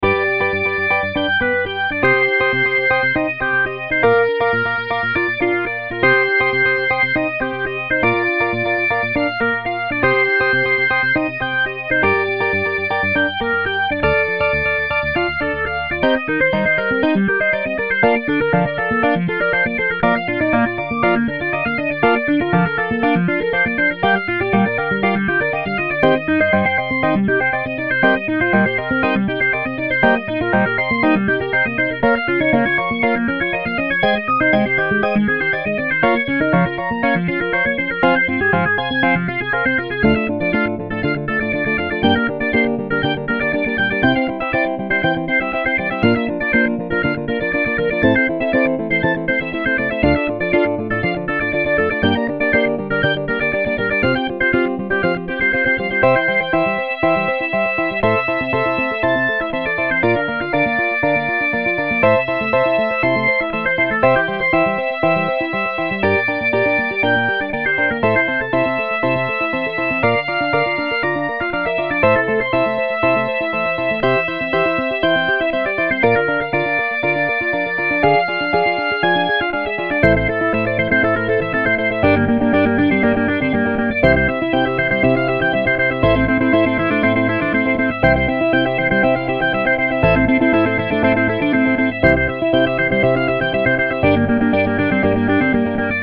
VA ロックオルガン、エレキギター、エレキベース